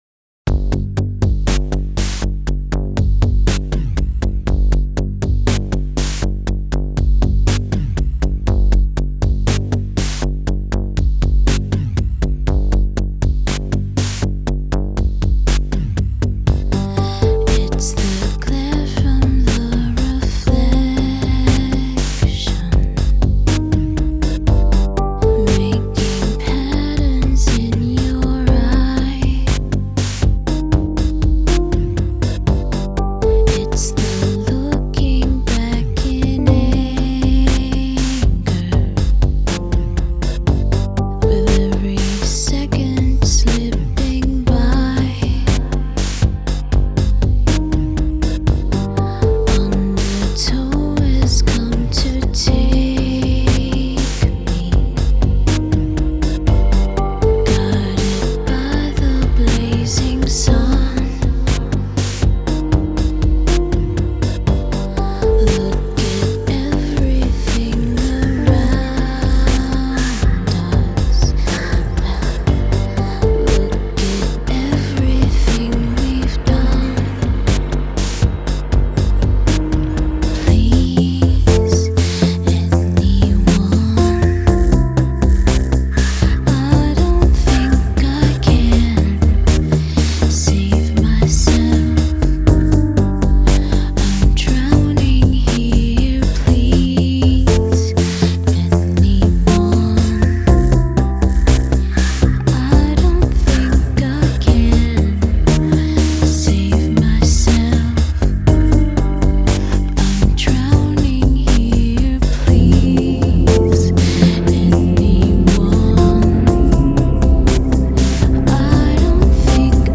It does have a NiN sound to it.